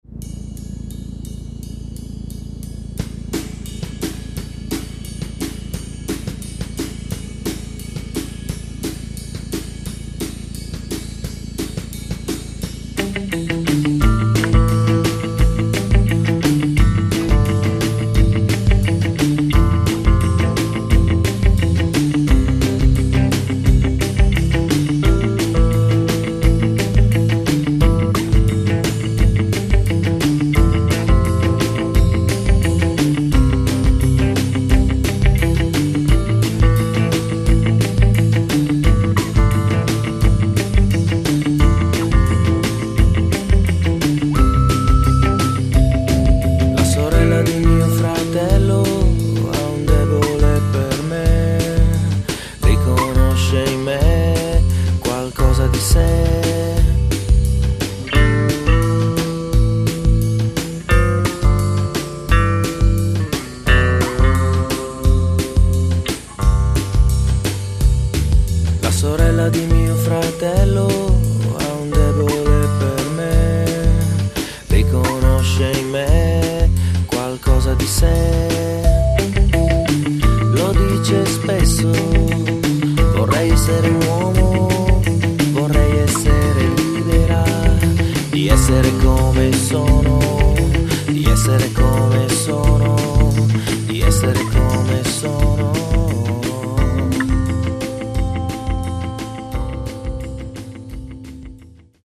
In quattro parole: ritmo, sudore, cuore e contenuti!